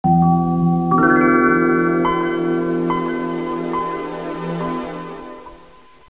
pure melody coming from some direction (10o) on the background of a strong wite noise coming from another direction (0o) would be listened as
puremelo.wav